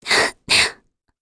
Talisha-Vox_Sad_kr.wav